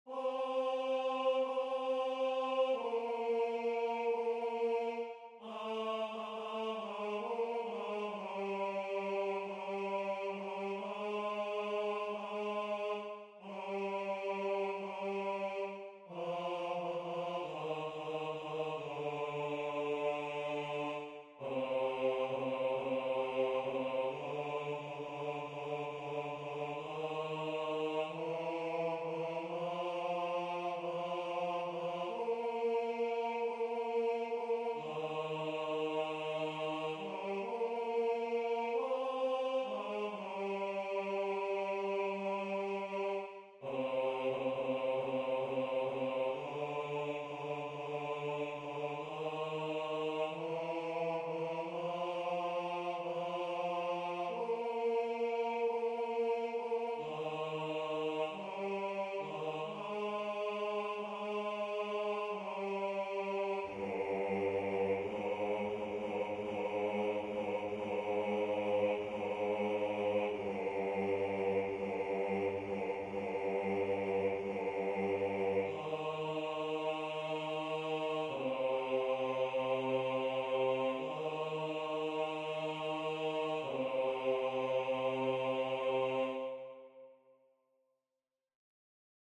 Rendu voix synth.
Basse